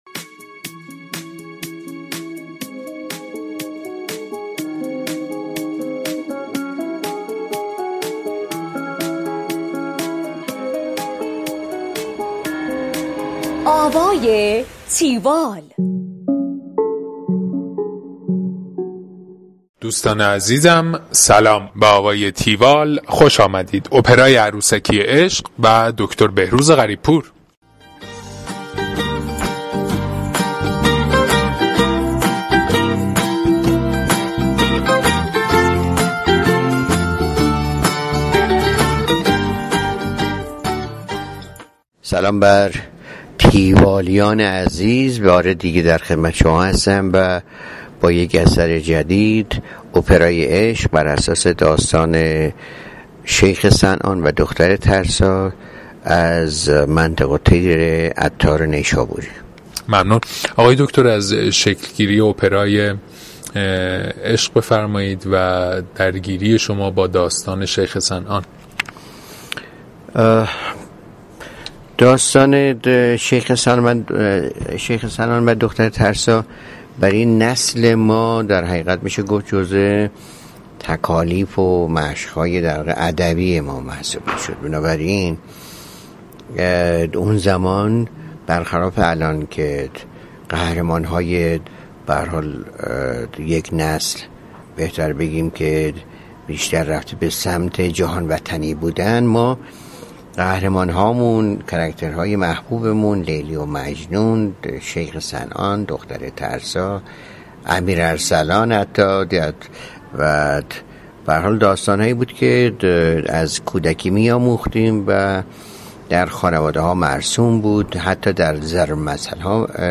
گفتگوی تیوال با بهروز غریب پور